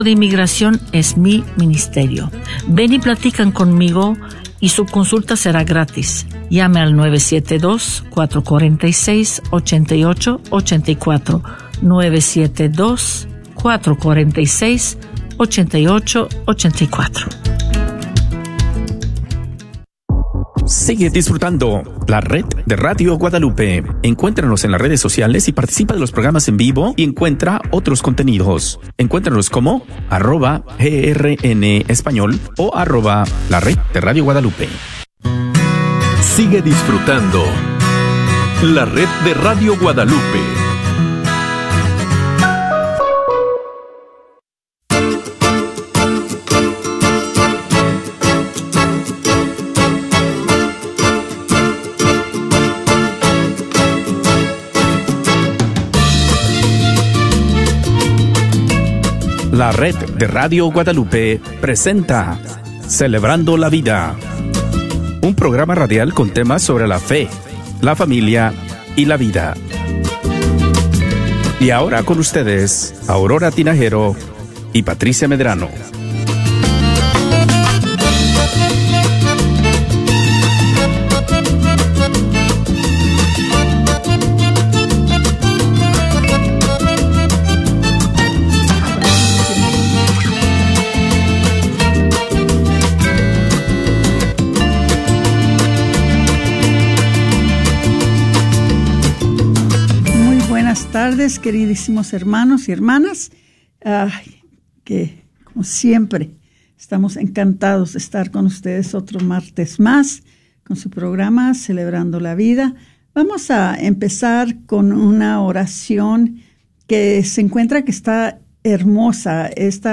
1 The Missing Link in Skin and Wellness | Interview